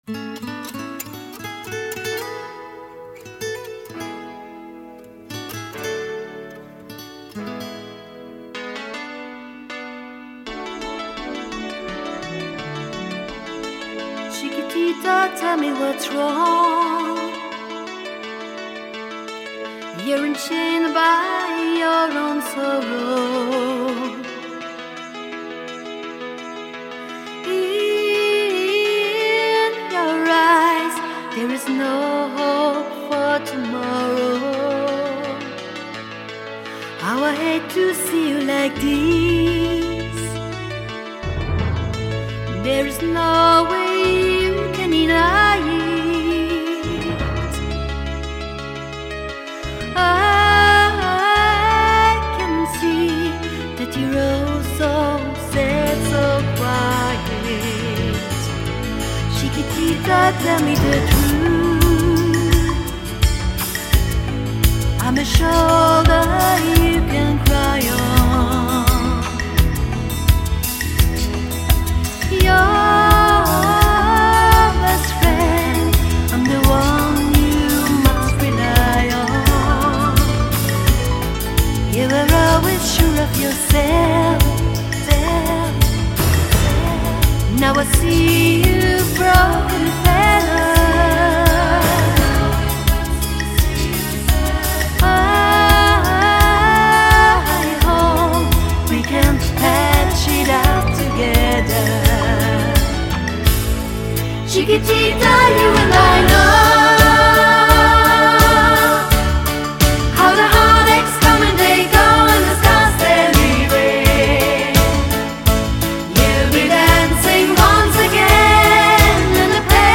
• Sänger/in